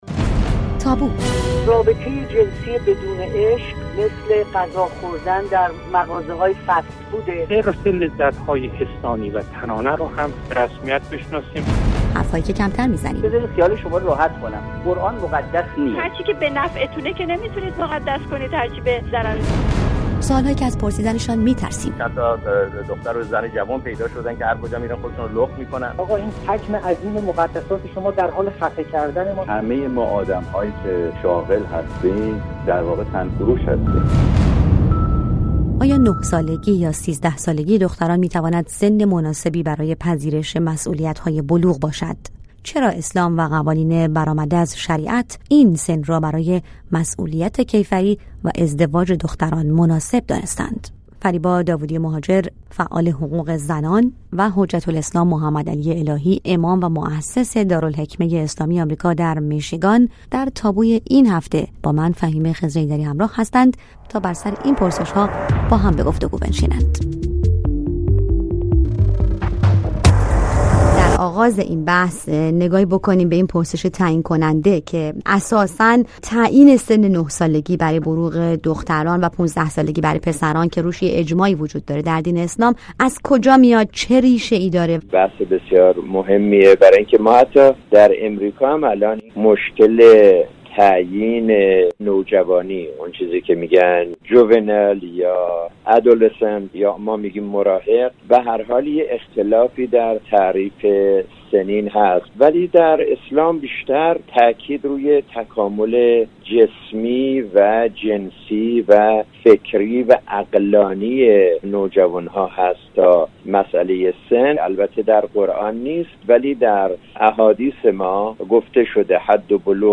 بر سر این پرسش‌ها به گفت‌وگو نشسته‌اند.